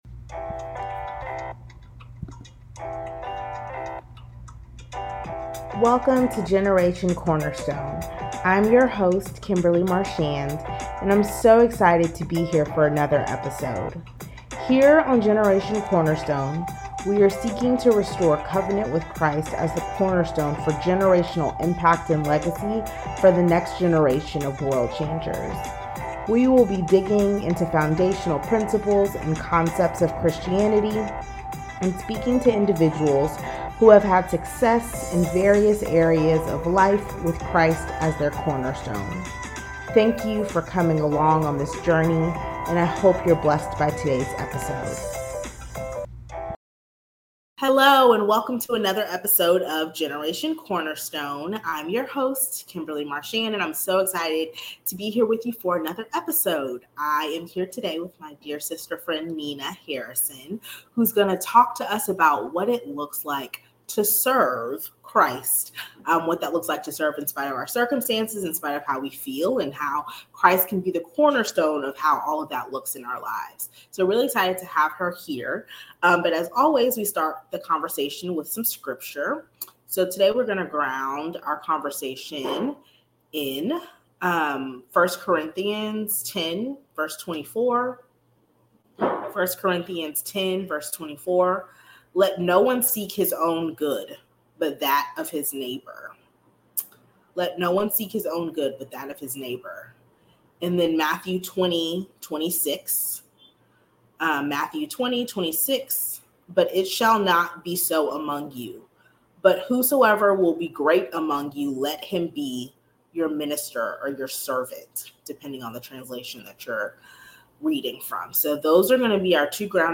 Check out this authentic conversation on what it means to serve with Christ as the cornerstone. What do we do when we don't feel like serving? Is Christ still the standard for how we serve one another in love?